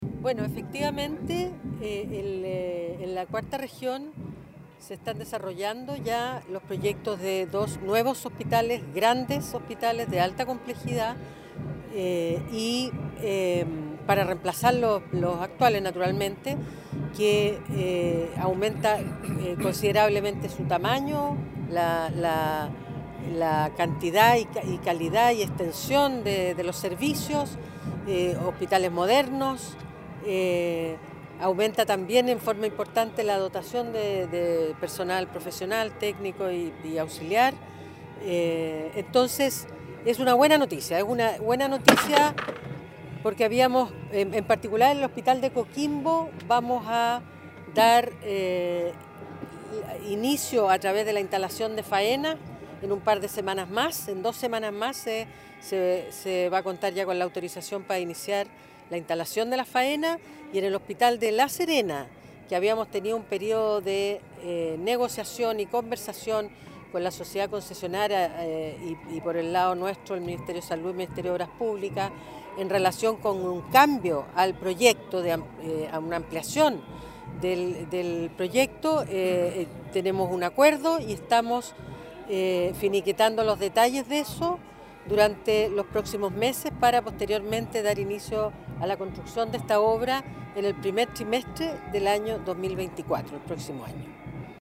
audio-Ministra.mp3